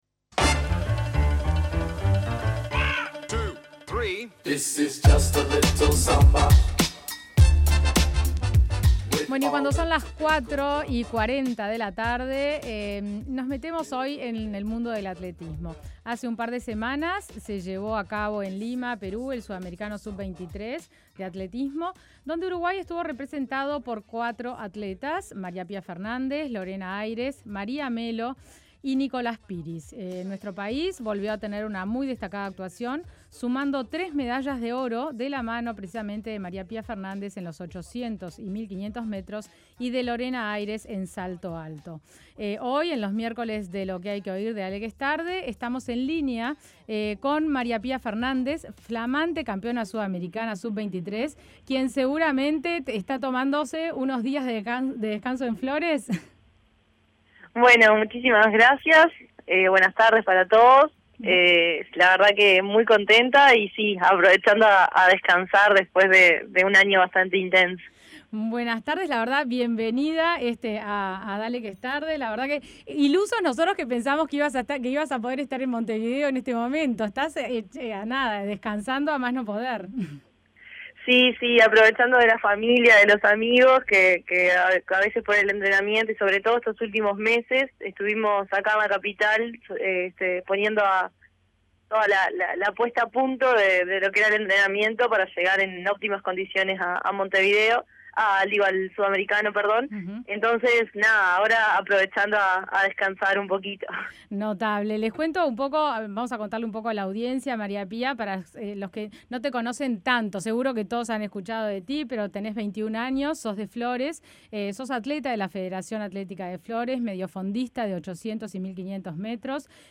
El miércoles 5 de octubre conversamos, en Dale que es Tarde, con la joven atleta celeste de qué significa su gran triunfo en Perú, cuál es el balance que hace de una larga temporada que empezó en los Panamericanos de Toronto 2015 y cómo se proyecta de cara a un nuevo ciclo olímpico.